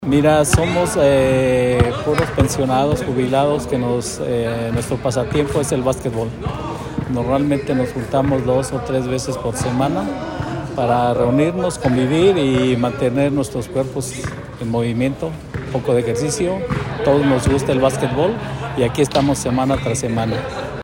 AudioBoletines